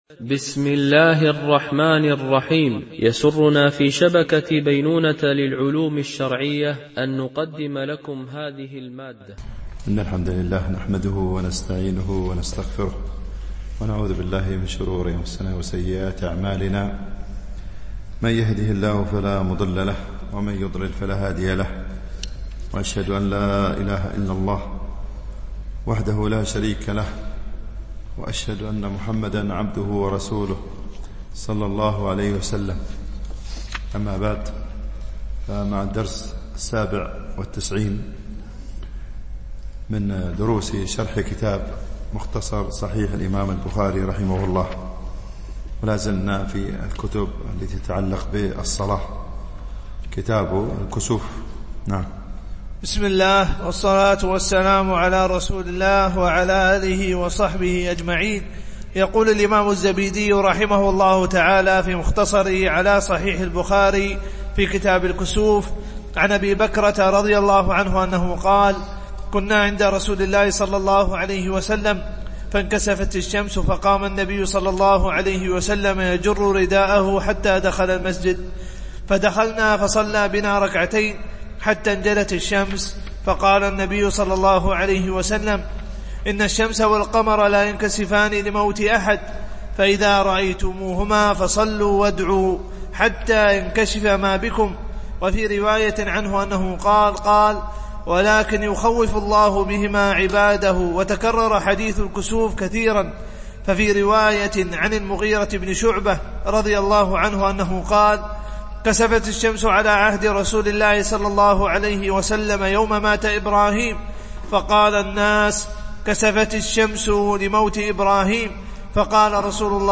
MP3 Mono 22kHz 32Kbps (CBR)